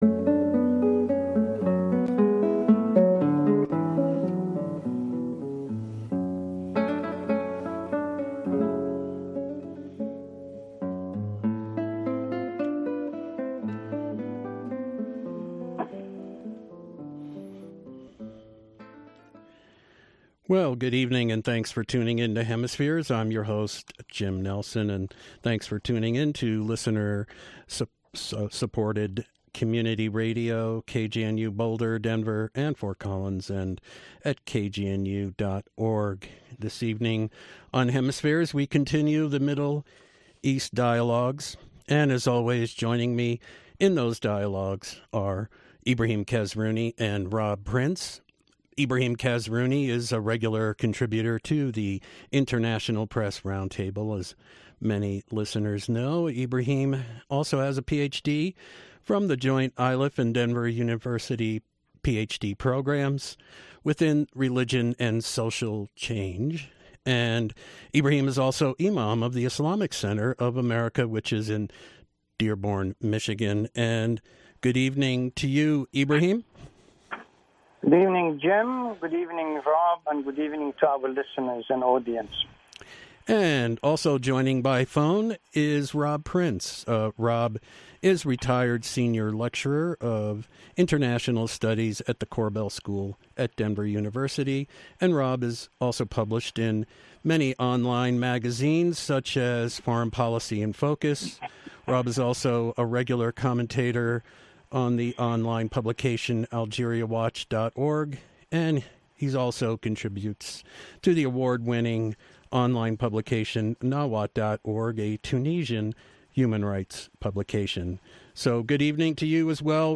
The audio is edited to shorten it about.